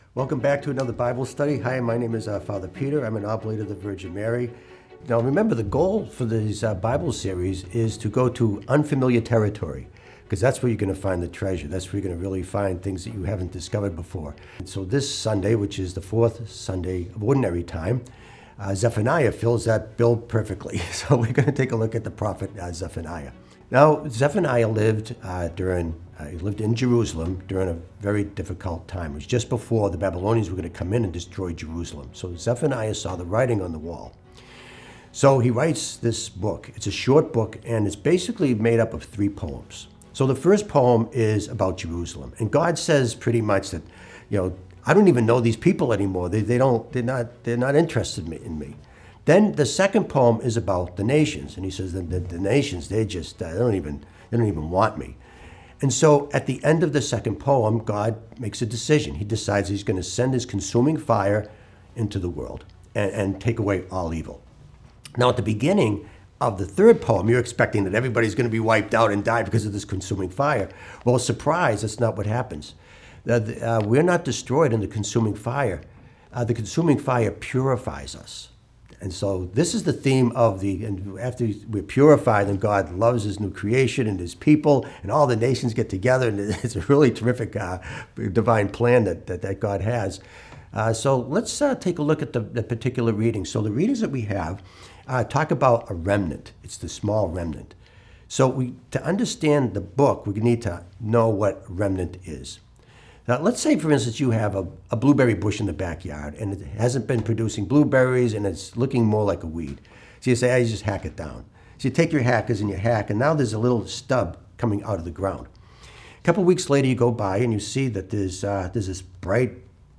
Homilies and Podcasts - St. Clement Eucharistic Shrine